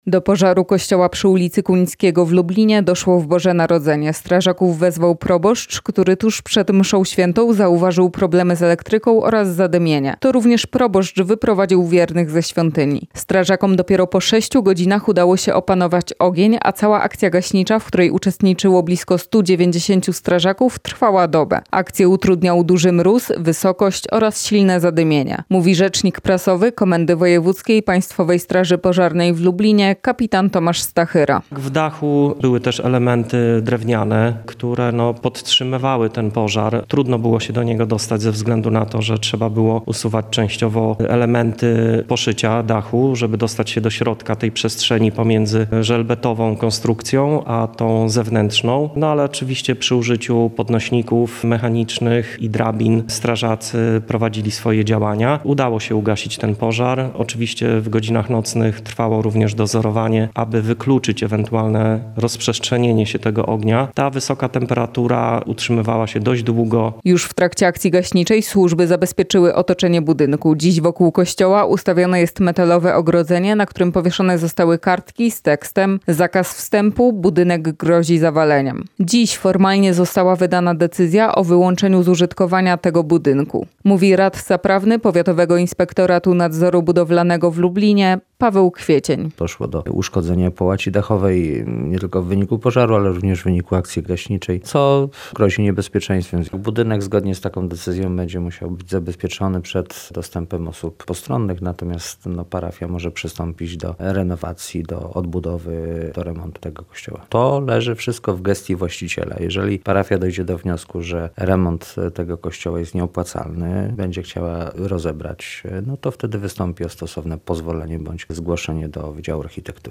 O szczegółach reporterka Radia Lublin